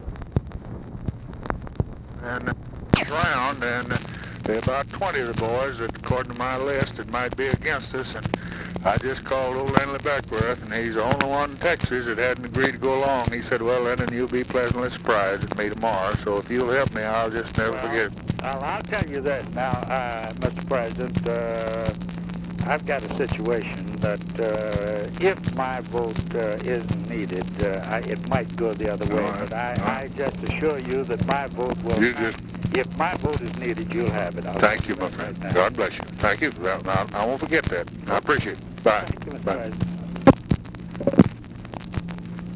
Conversation with VAUGHAN GARY, April 7, 1964
Secret White House Tapes